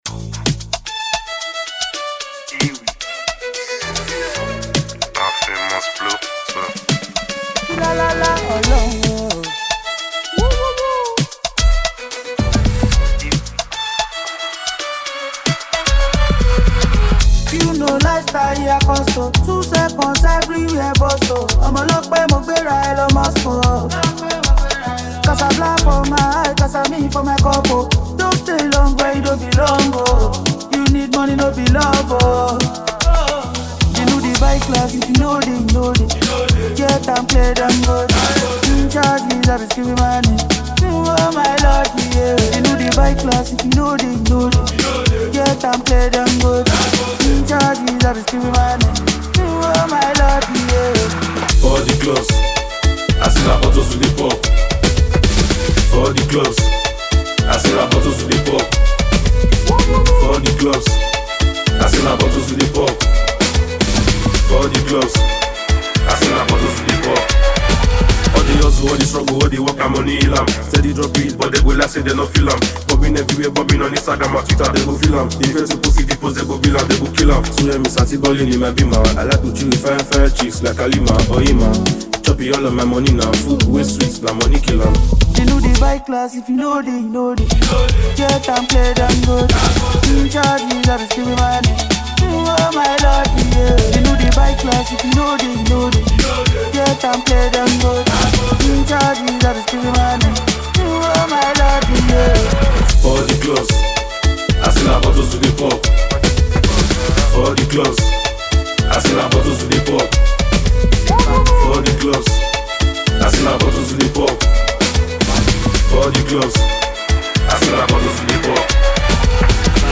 Category Nigerian Music
Genre Afrobeats